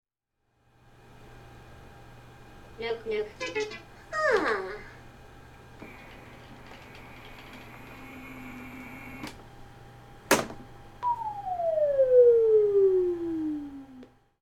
Pepper The Robot- Shutdown sequence
• Social humanoid robot